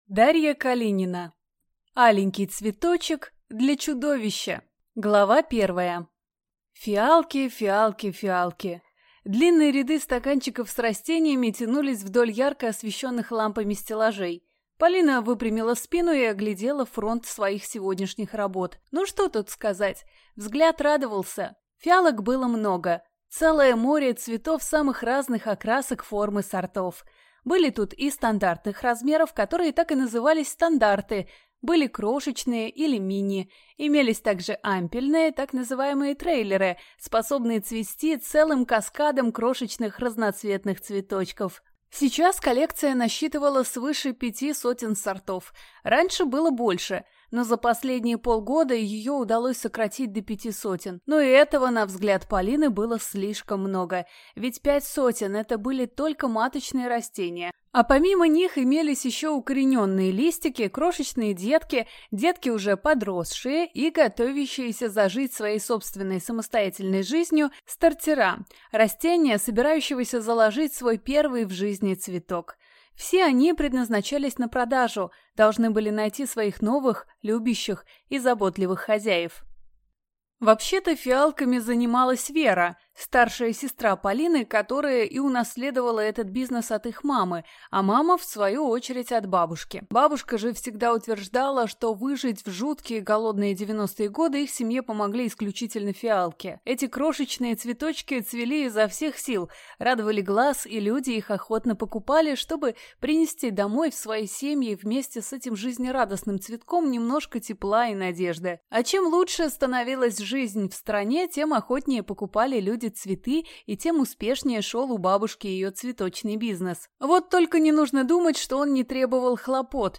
Аудиокнига Аленький цветочек для чудовища | Библиотека аудиокниг